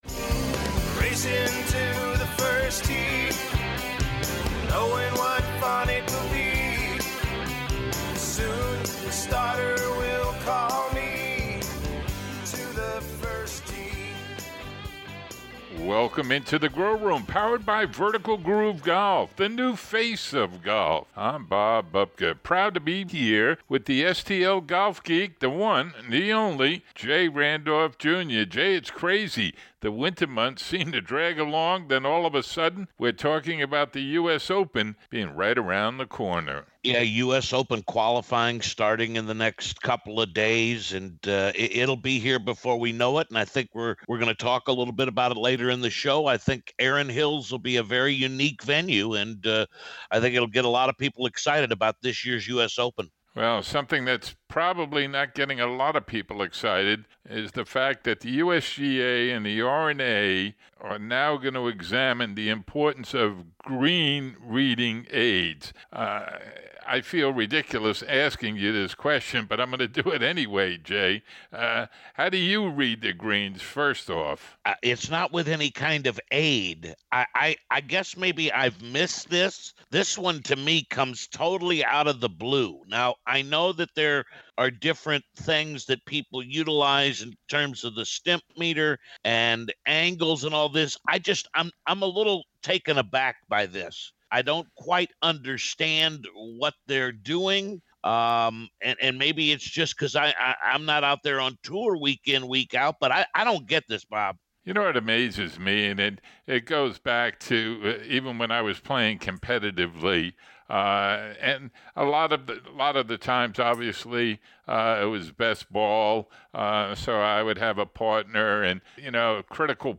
Previews on the menu: Wells Fargo Championship and Insperity Invitational. Feature Interview: John Daly talks about his choice to play the Vertical Groove Driver.